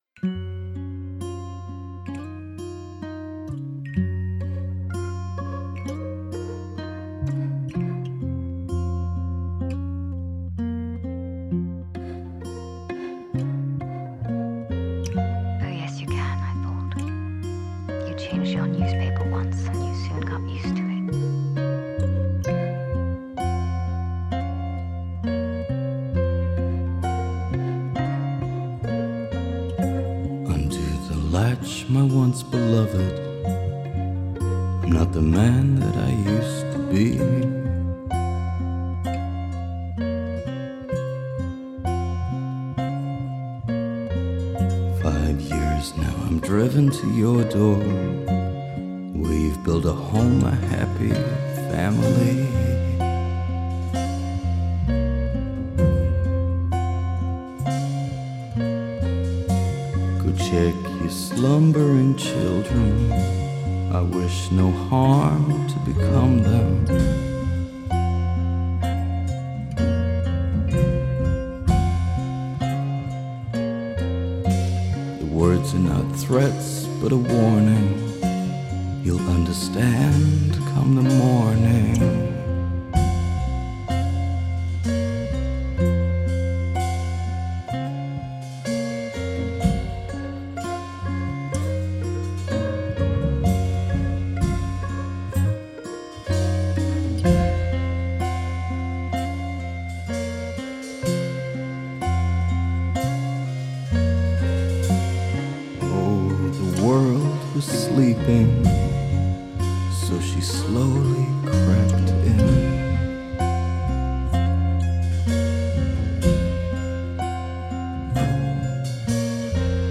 but they are still full of a slow, sad longing.